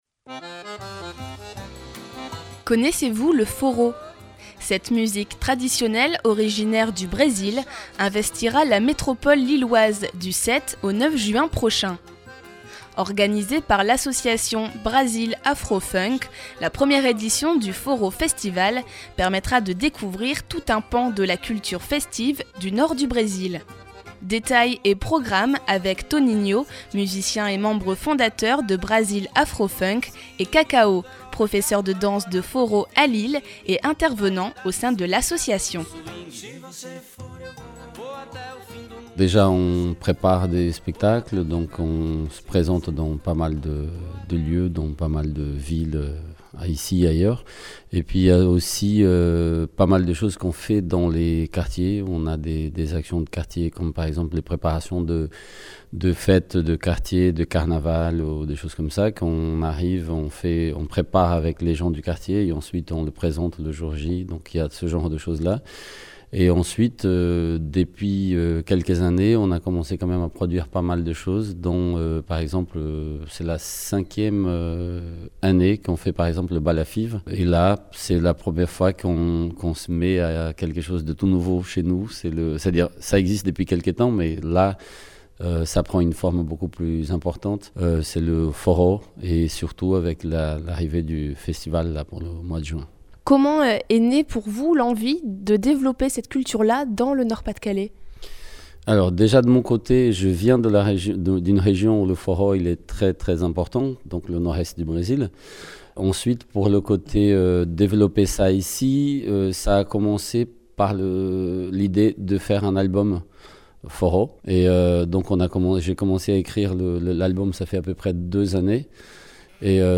Interview Radio RPL – 1ère Classe | Vamo que Vamo – Festival Forró de Lille #9
Écoutez l’interview de l’émission 1ère Classe de RPL 99FM sur la 1ère édition du Festival Forró Lille en 2013.